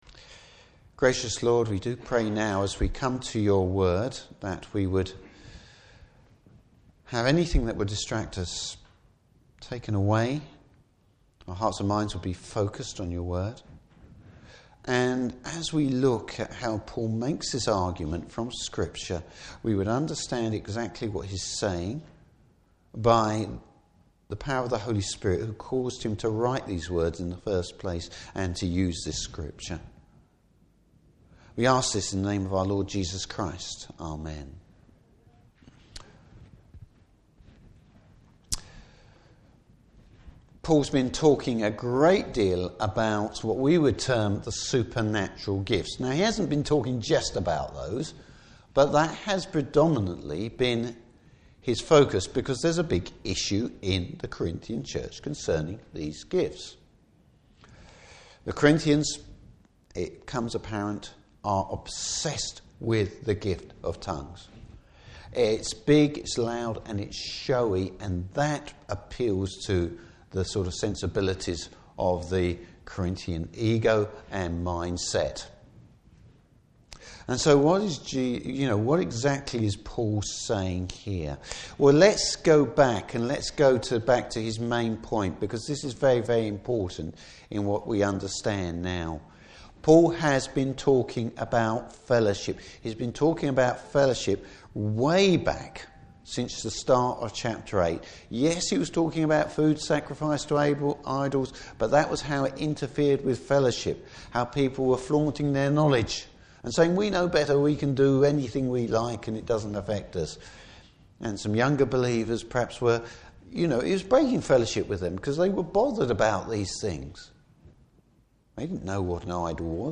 Service Type: Morning Service What bearing does a text from Isaiah have on the use of the gift of tongues?